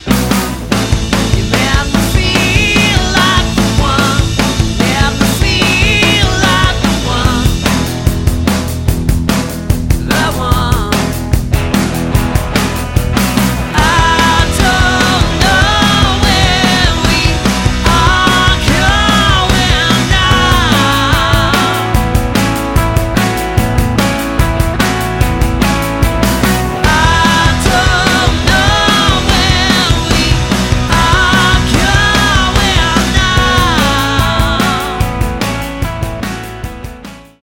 • Unique and stylised versions of classic anthems